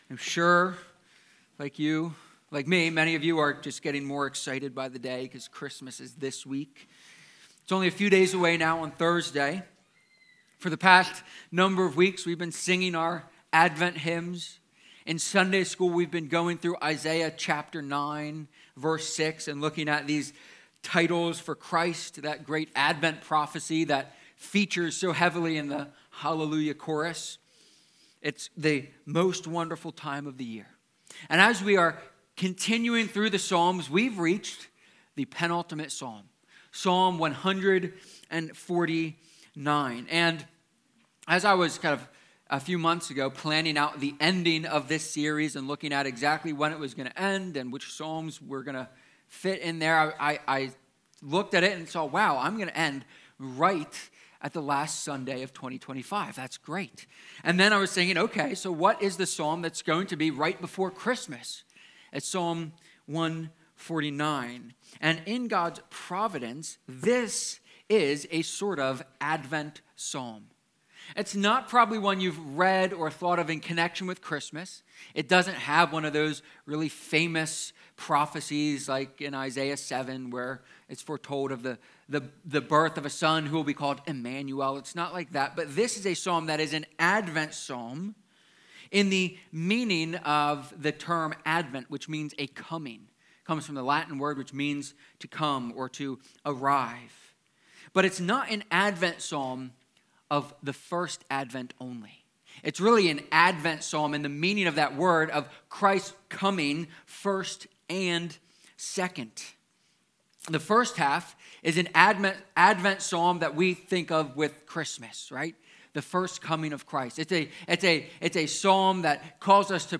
Psalm-149-sermon.mp3